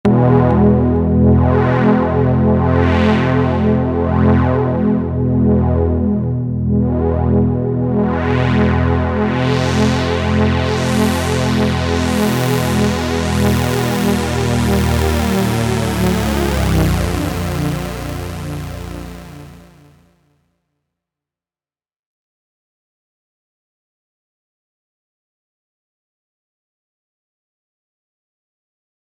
Single-cycle unison chord waveform
Created from single cycle Major chord(Sine wave).